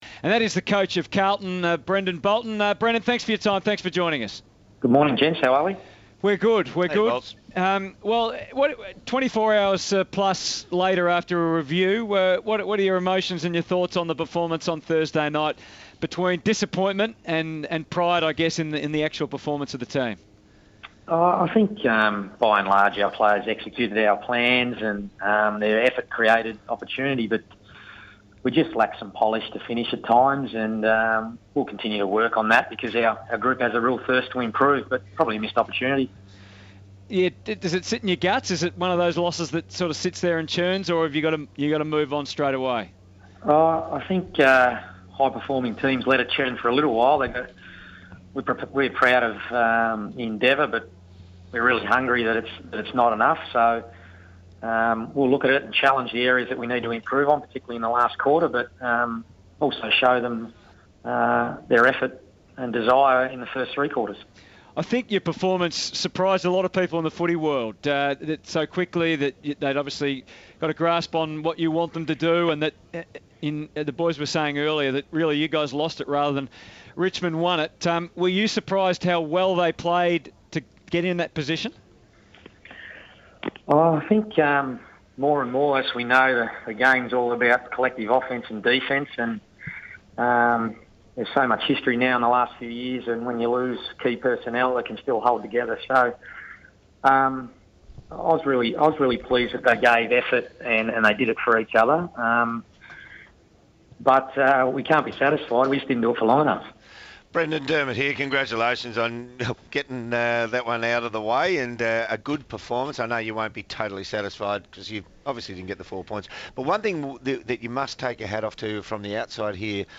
Carlton coach Brendon Bolton speaks to SEN 1116's Crunch Time after the Blues' 2016 season opener.